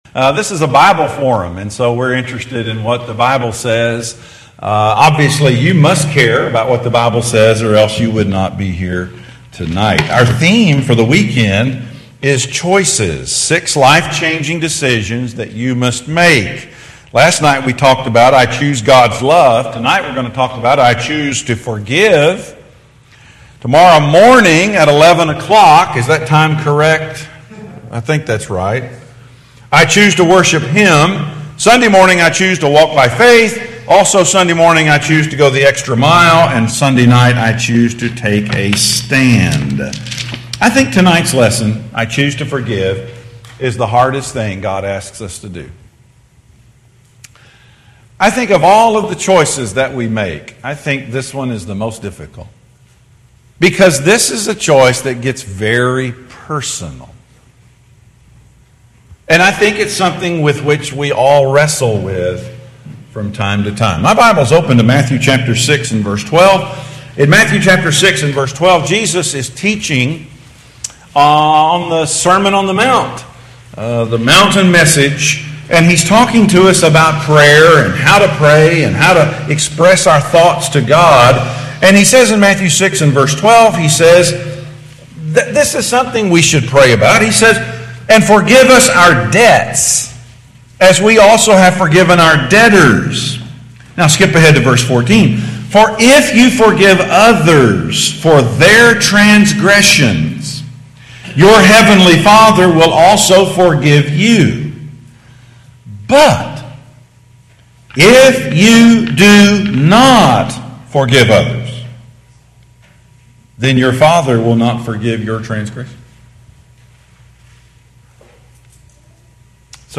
Series: Gettysburg 2017 Bible Forum, I Choose